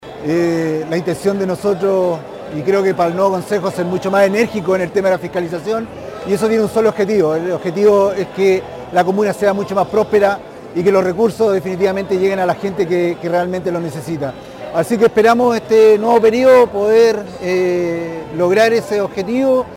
Por último, el concejal Rodrigo Hernández remarcó su rol como fiscalizador.